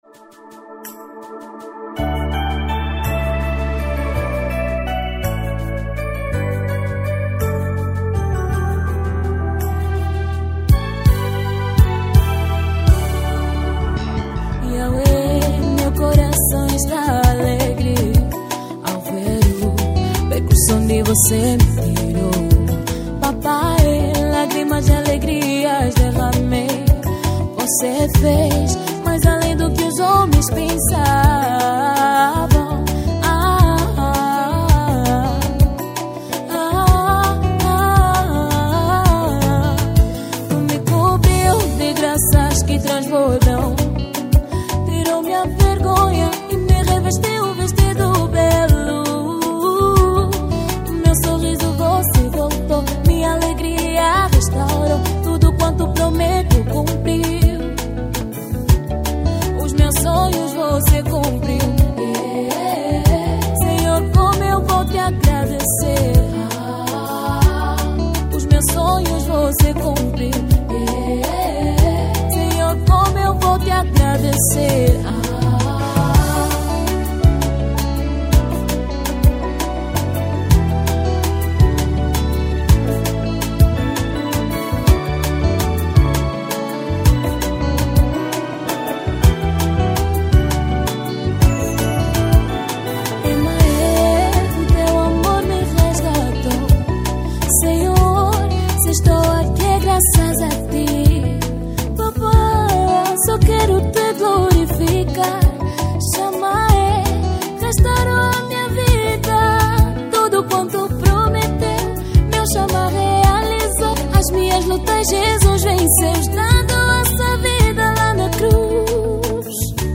Gospel 2025